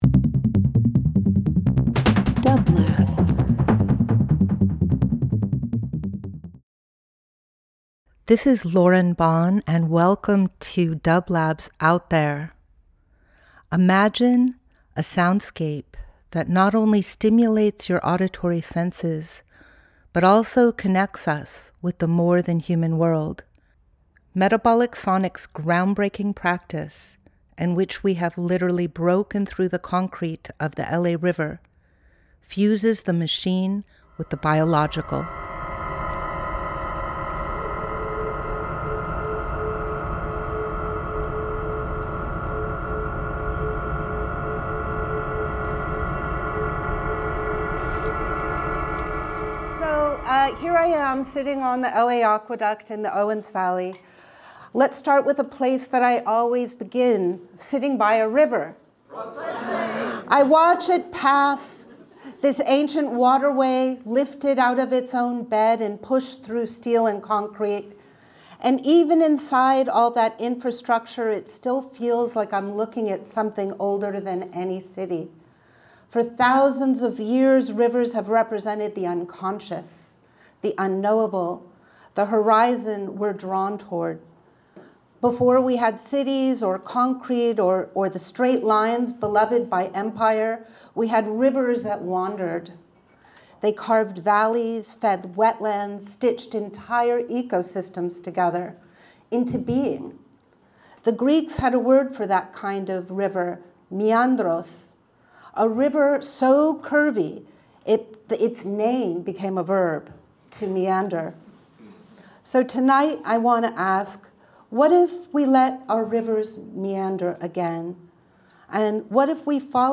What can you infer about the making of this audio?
Each week we present field recordings that will transport you through the power of sound.